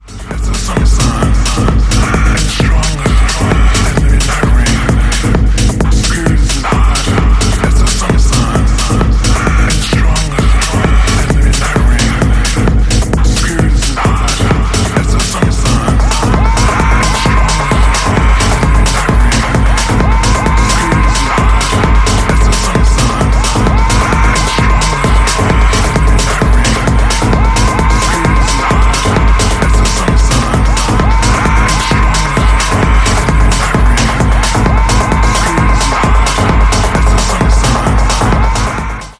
淡々と重厚なボトムにフリーキーなテクスチャーを重ねた
90sのUSテクノに通じるエネルギーで深い時間をハメるテクノ・トラックス